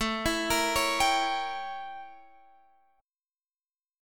A7b9 chord